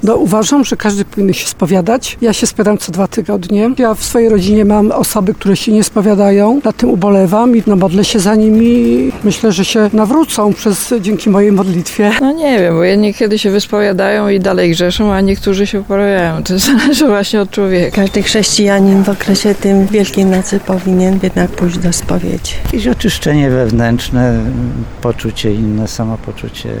Sami tarnowianie podkreślają, że spowiedź, szczególnie przed Wielkanocą jest dla katolika ogromnie ważna.
15ludzie-spowiedz.mp3